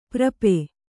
♪ prape